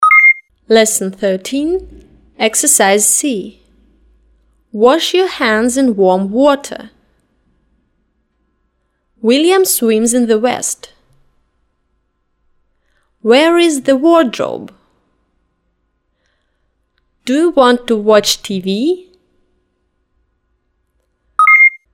Фонетический курс английского языка — часть 54
На этой странице выложена часть № 54 фонетического аудиокурса английского языка.